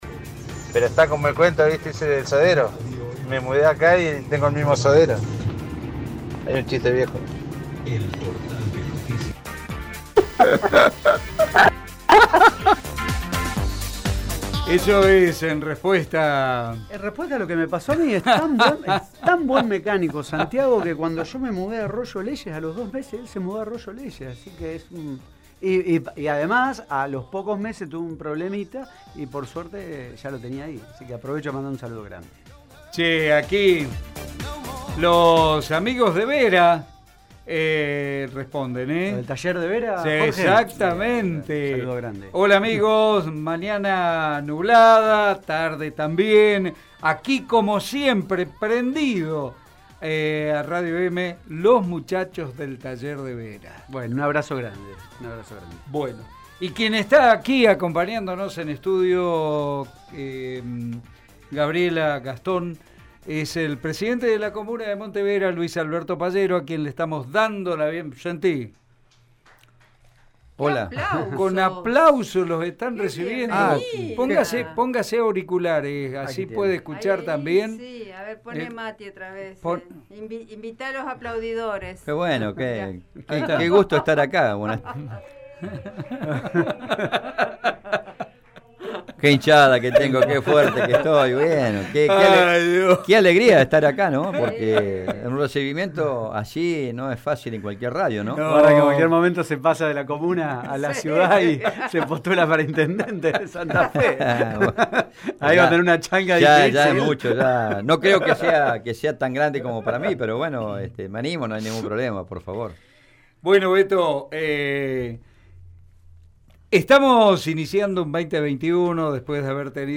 El presidente comunal de la localidad de Monte Vera, Luis Alberto Pallero, visitó este miércoles los estudios de Radio EME.